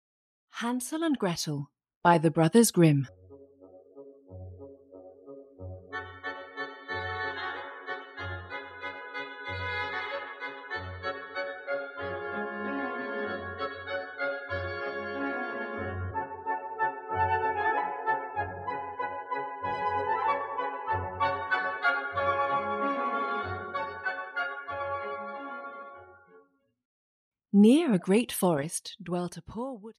Hansel and Gretel, a Fairy Tale (EN) audiokniha
Ukázka z knihy